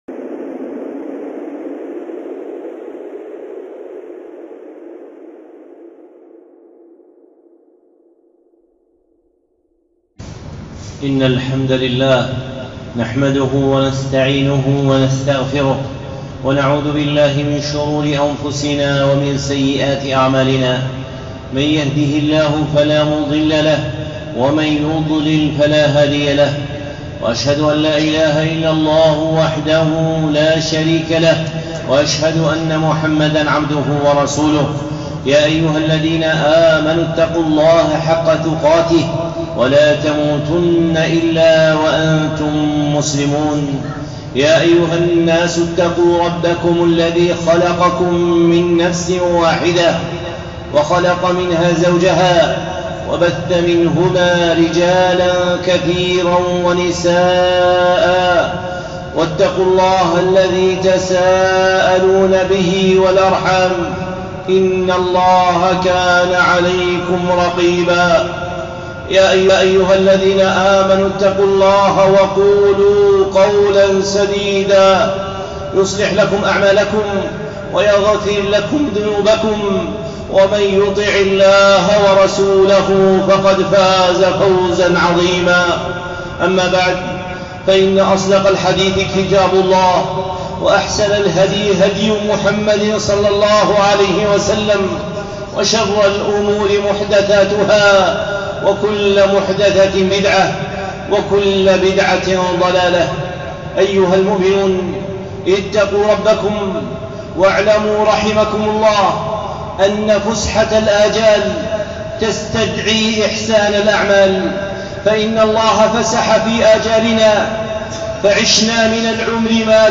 خطبة (نفحة بيان لاستقبال رمضان)